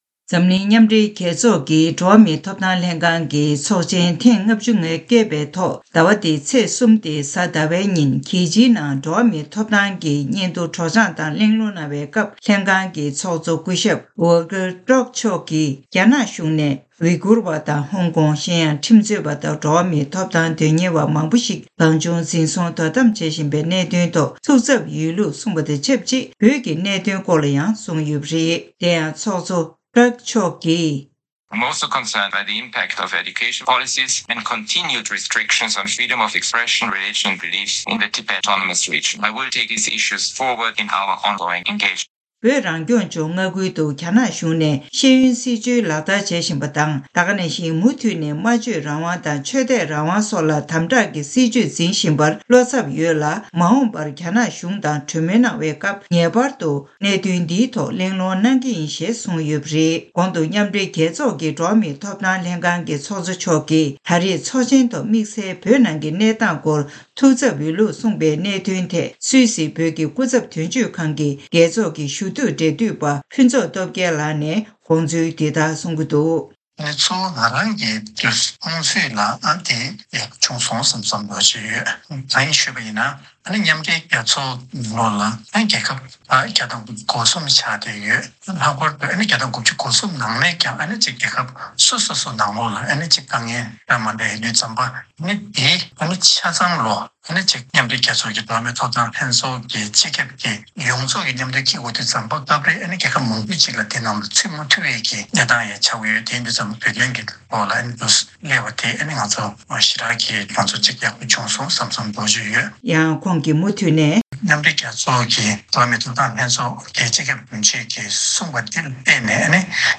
གསར་འགྱུར་དཔྱད་གཏམ་གྱི་ལེ་ཚན་ནང་།